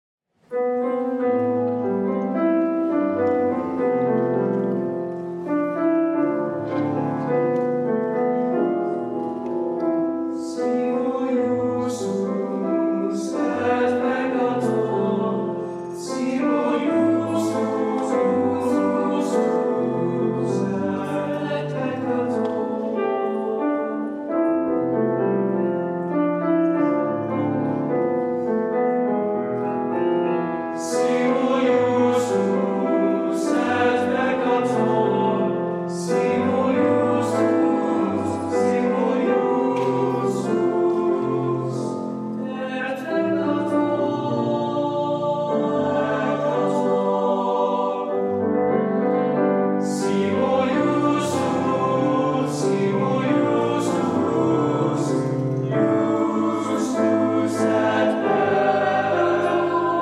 four curricular choirs and two a cappella ensembles
Sing With Heart: Spring Concert, 2019
Piano
With: Mustang Chorale